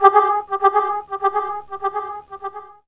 2607L SYNDUB.wav